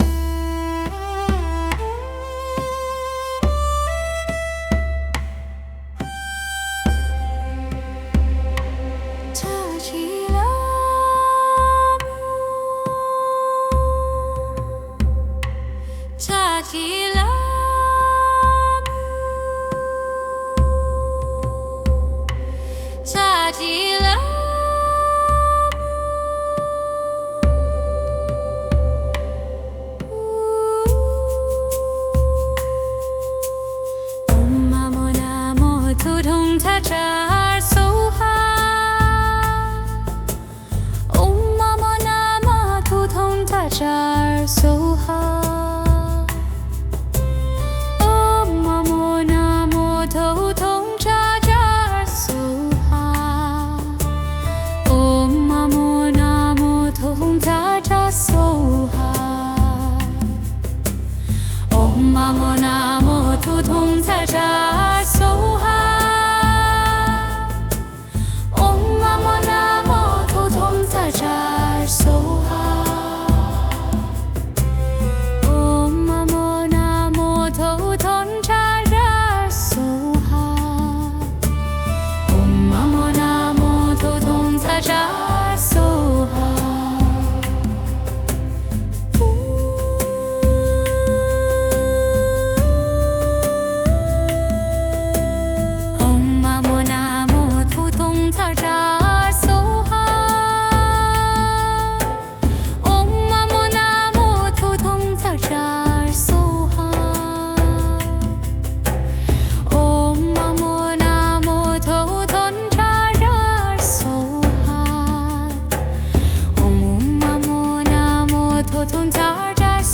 Zashi Lhamo mantra song.wav